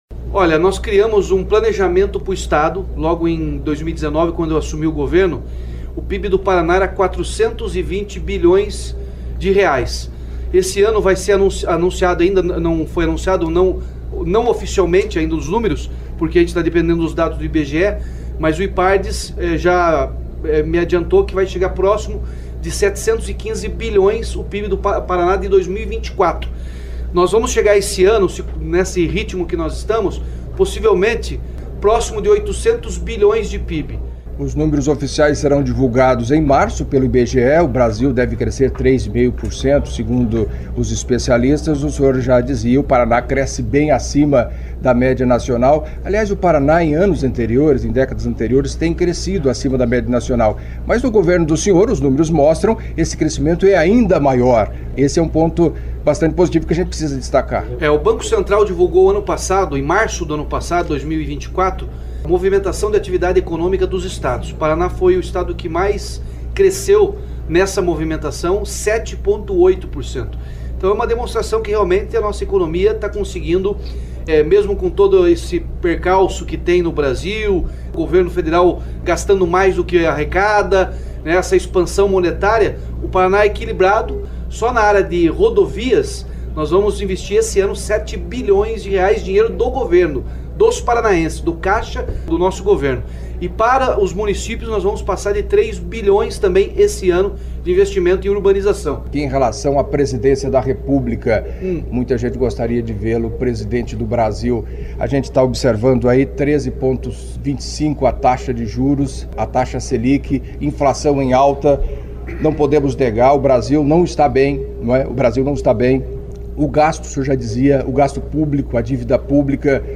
Player Ouça Ratinho Júnior, governador do Paraná